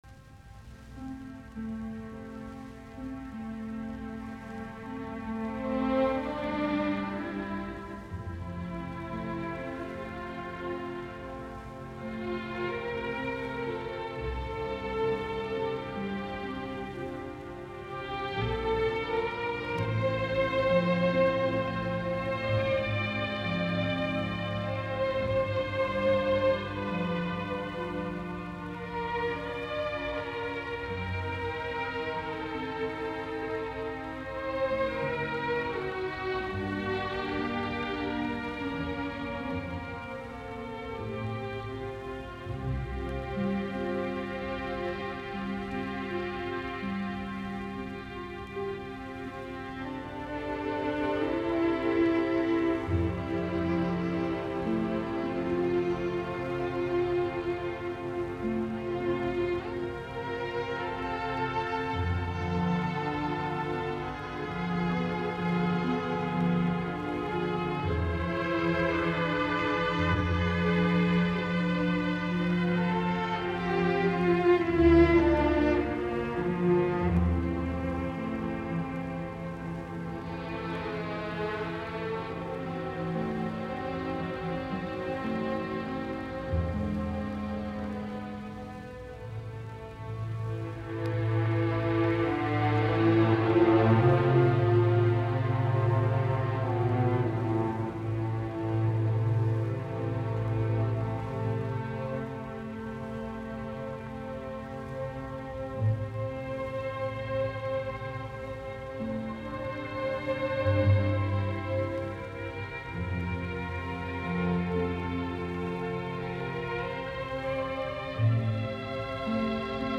Sinfoniat, nro 5, cis-molli